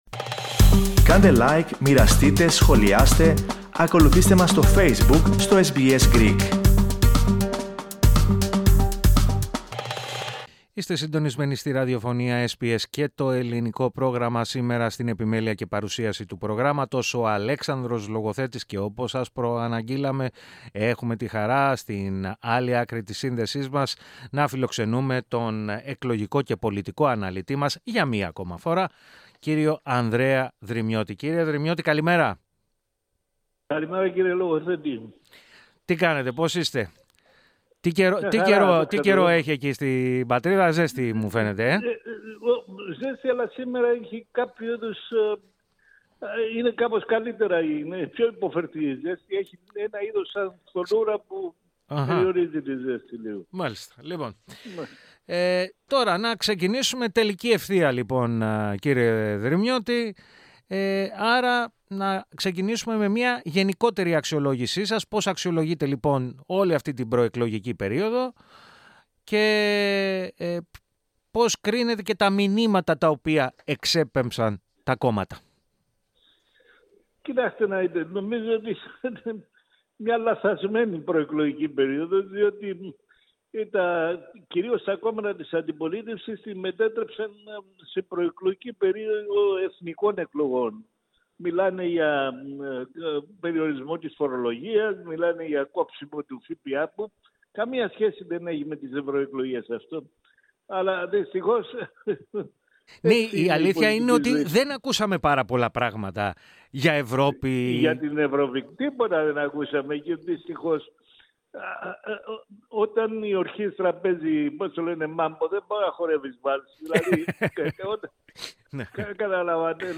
μίλησε στο Ελληνικό Πρόγραμμα της ραδιοφωνίας SBS